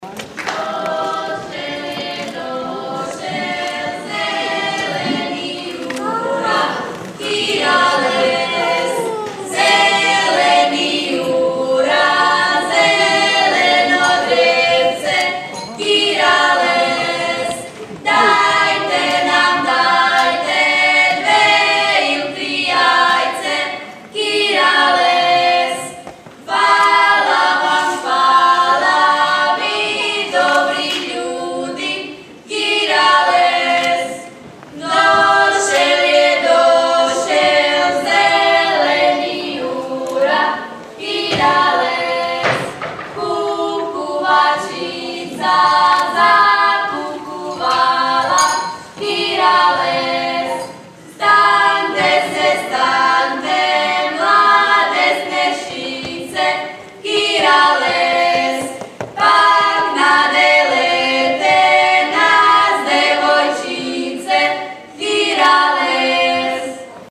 Christmas Show Songs – 2017
3rd Grade – Jurjevo